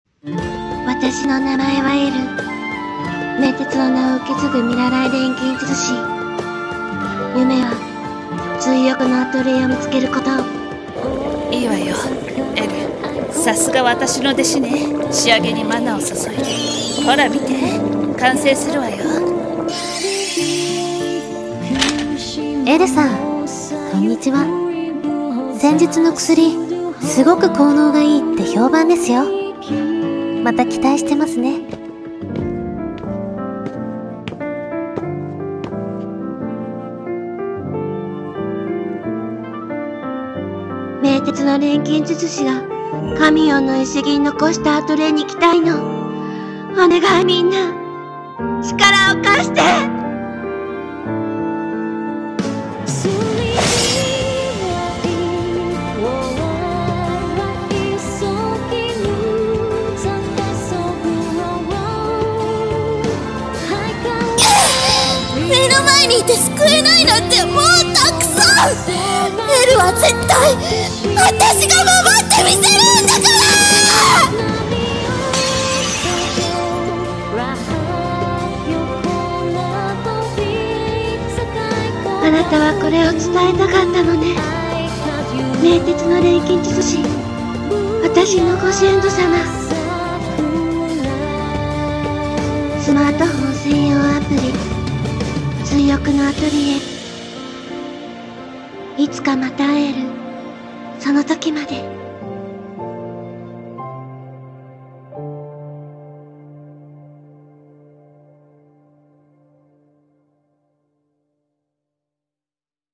【多人数声劇】追憶のアトリエ